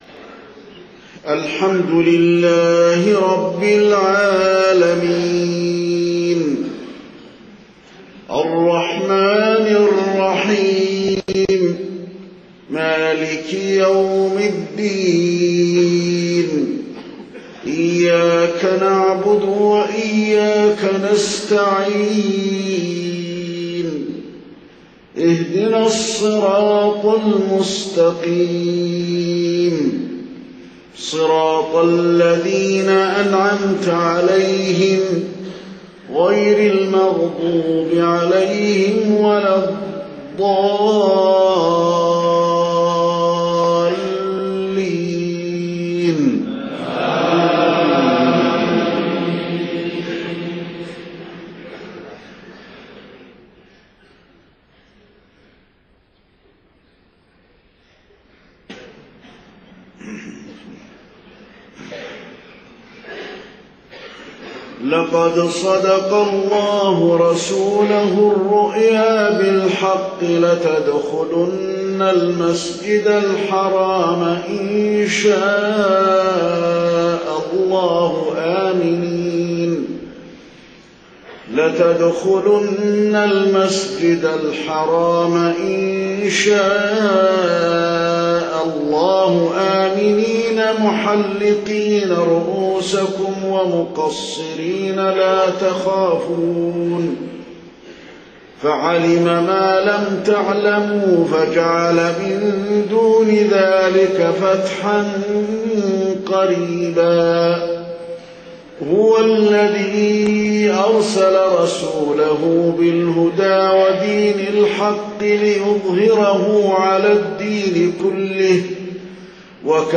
صلاة العشاء 30 ذو الحجة 1429هـ خواتيم سورتي الفتح 27-29 و الذاريات 55-60 > 1429 🕌 > الفروض - تلاوات الحرمين